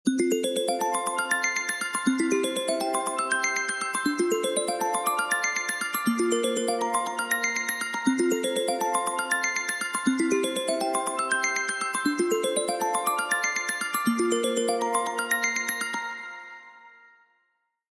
macOSsystemsounds
Waves.mp3